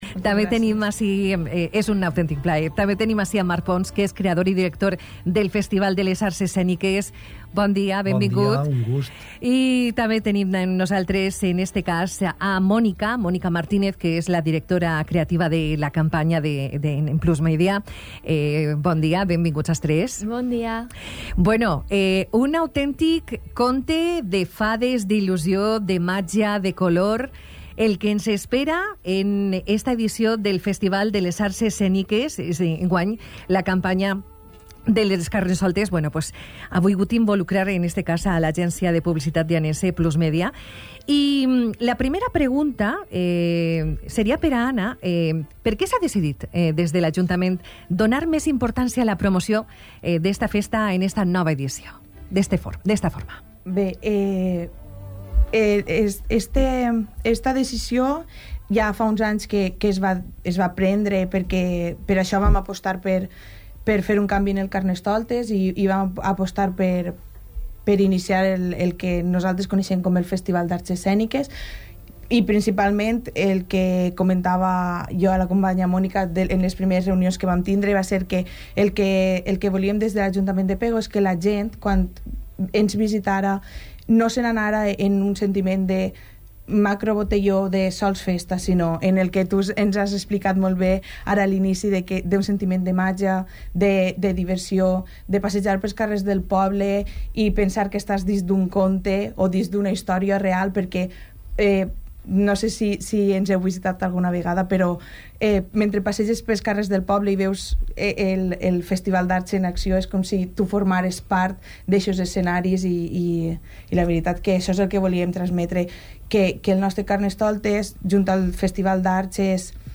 Entrevista-Carnaval-Pego.mp3